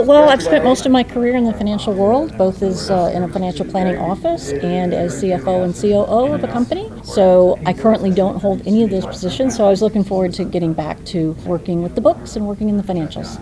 Muntin told WKHM that her desire to get back into working with finances drew her to the job.